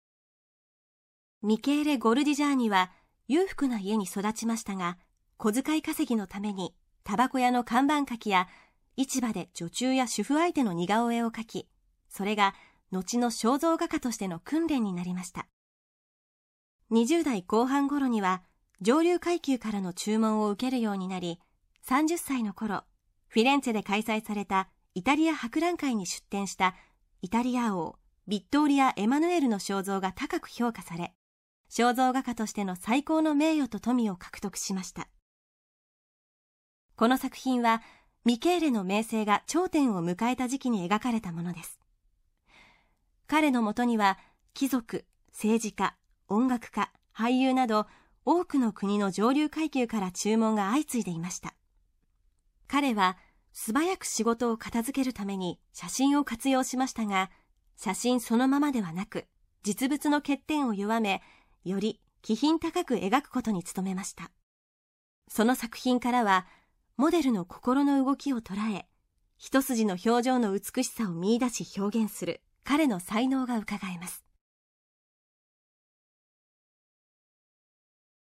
作品詳細の音声ガイドは、すべて東京富士美術館の公式ナビゲーターである、本名陽子さんに勤めていただいております。